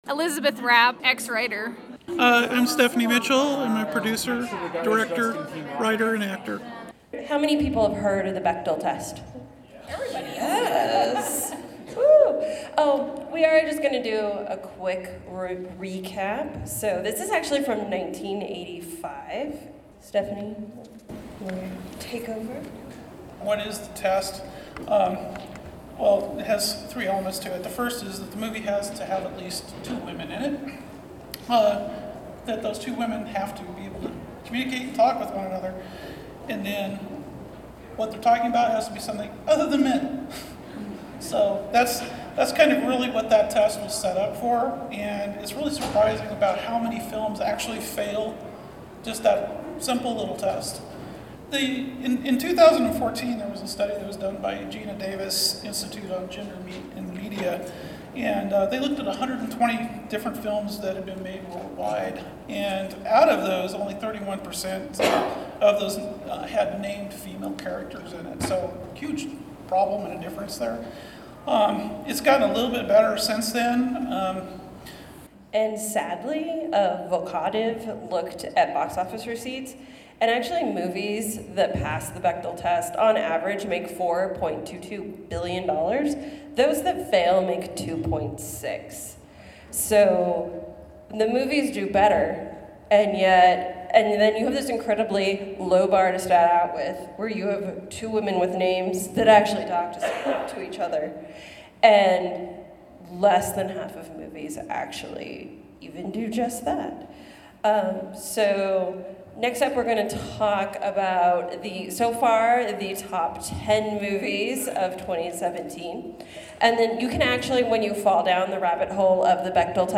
The Denver Film Society hosted a panel on the lack of female representation in comics.  The discussion focused on The Bechdel Test (which asks whether a work of fiction features at least two women who talk to each other about something other than a man) and its relevance to current pop and film culture.